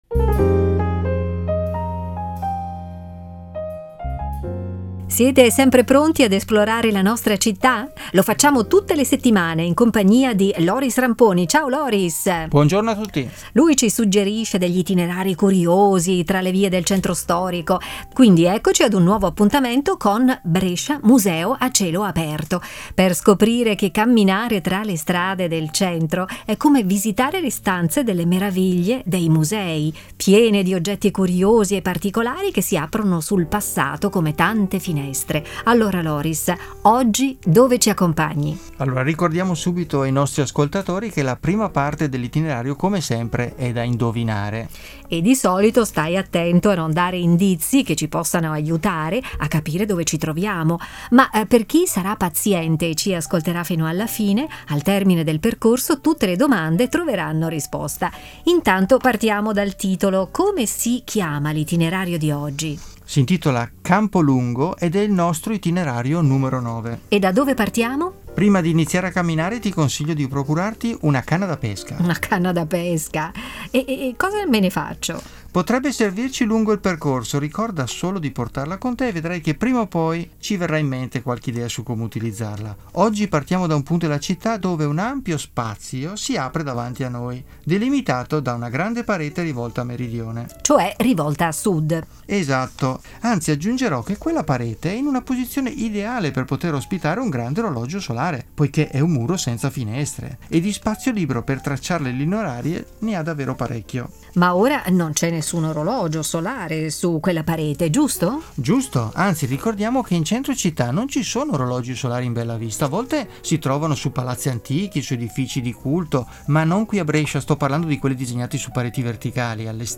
audio-guida e itinerari per passeggiare tra le vie di Brescia